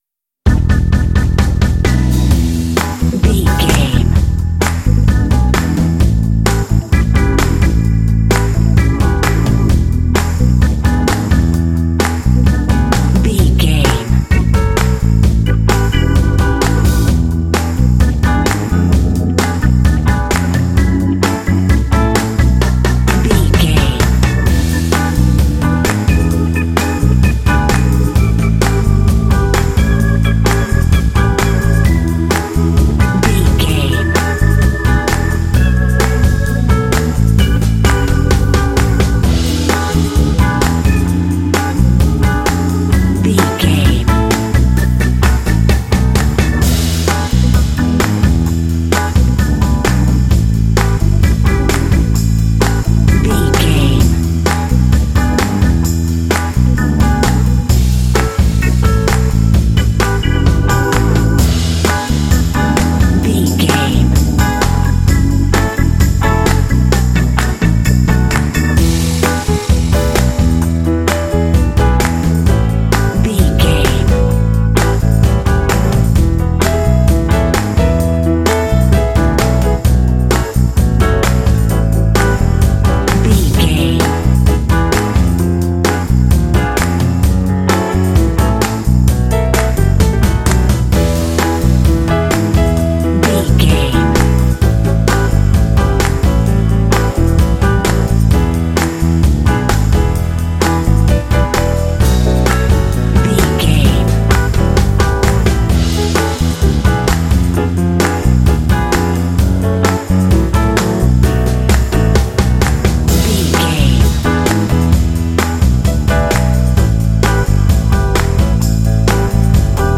Epic / Action
Aeolian/Minor
lively
energetic
groovy
electric organ
bass guitar
electric guitar
drums
piano